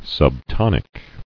[sub·ton·ic]